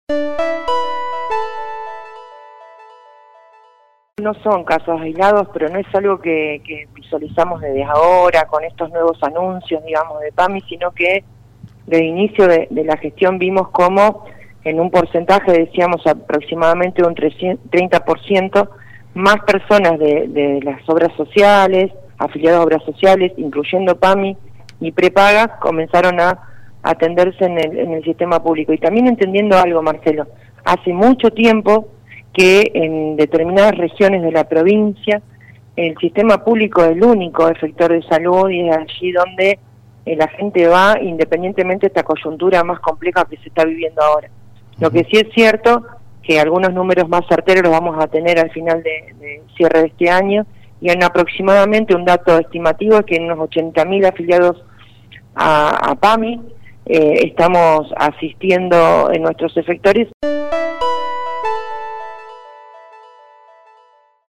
En contacto con LT3 la Ministra de Salud de Santa Fe, Silvia Ciancio, dijo que también personas con obra social recurren a efectores de la provincia.